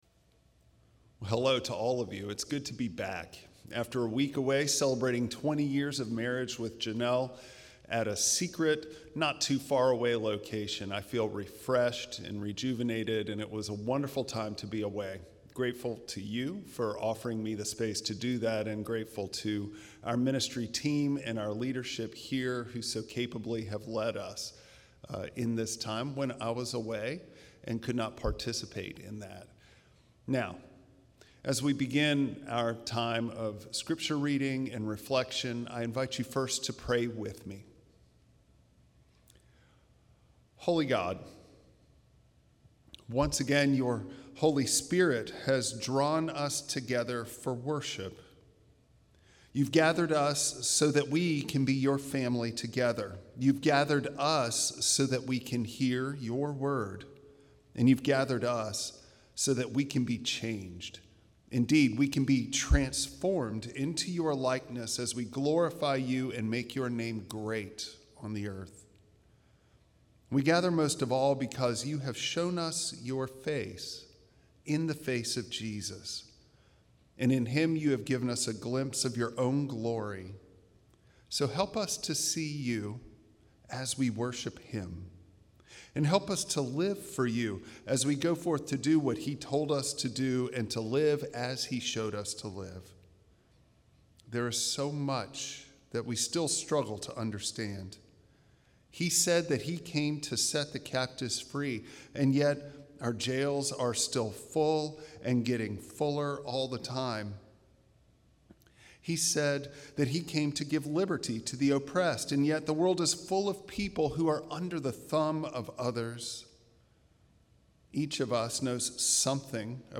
Bible Text: Luke 4:14-21 | Preacher